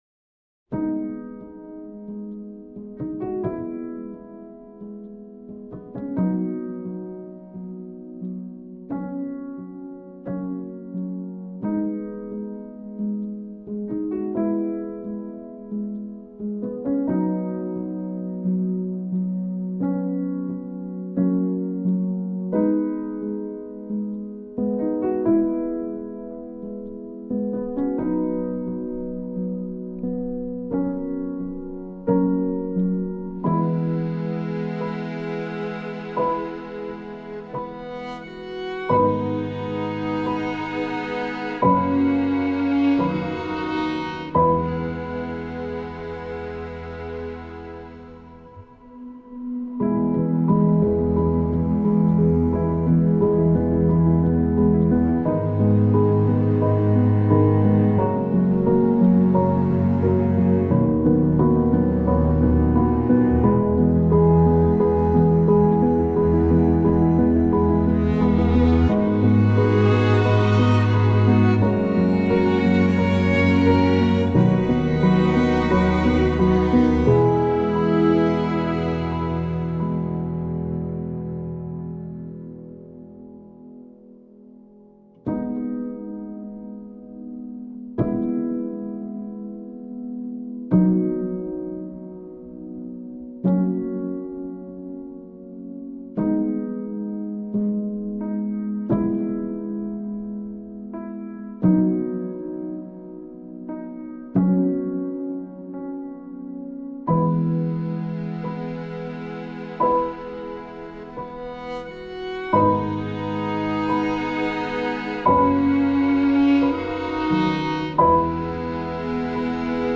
Trailer Music , پیانو , سینمایی , موسیقی بی کلام , ویولن